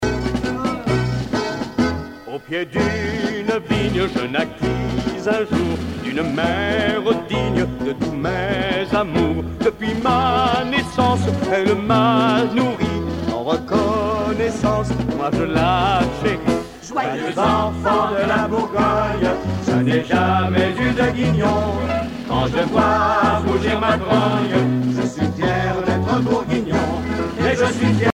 circonstance : bachique
Genre strophique